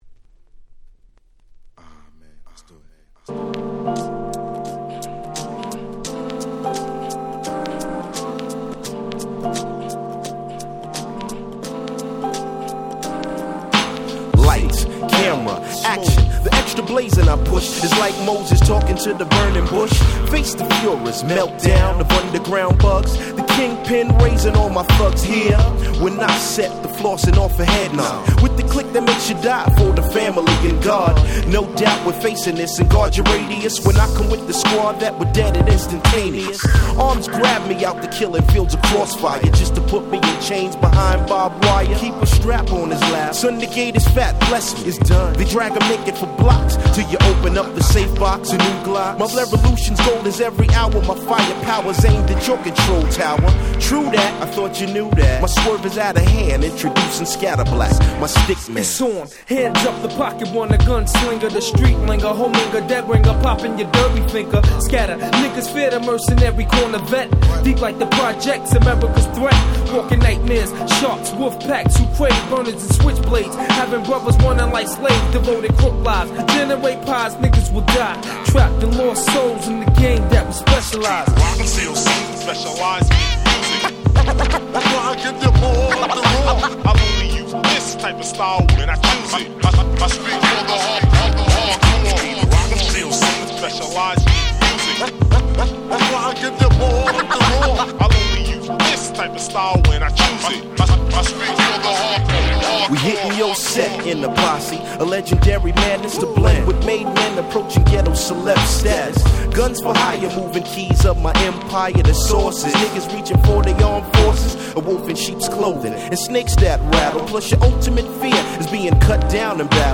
95' Super Nice Hip Hop !!